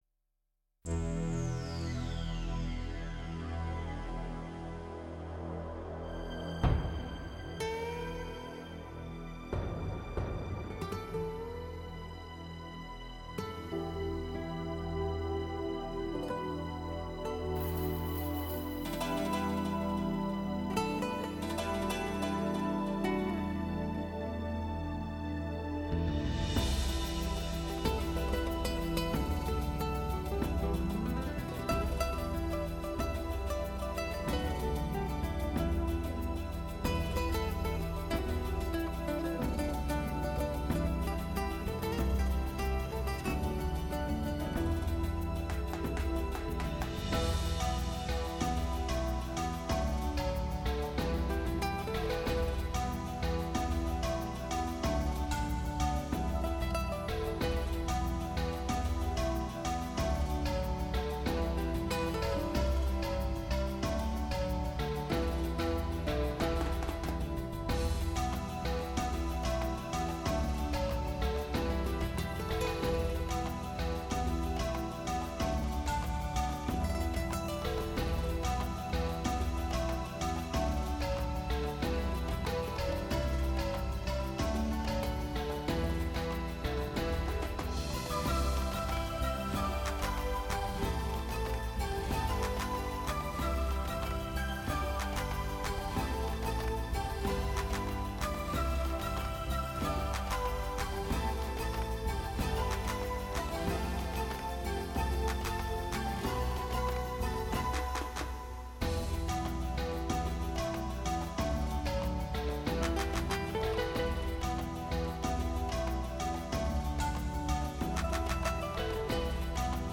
音乐类型：轻音乐/纯音乐